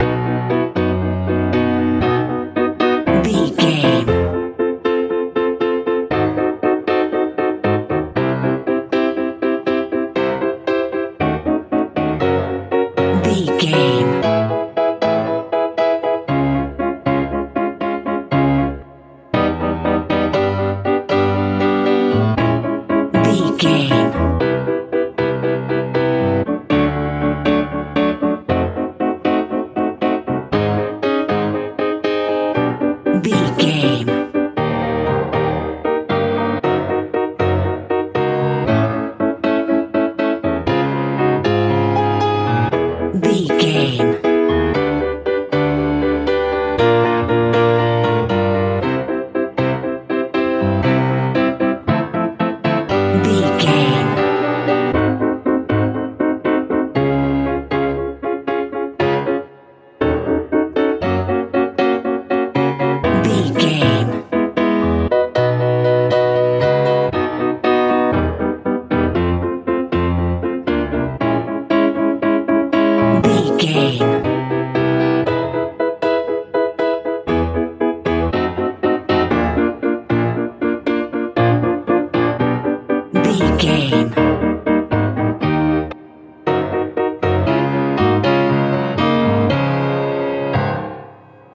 lite pop feel
Ionian/Major
bright
magical
piano
80s
90s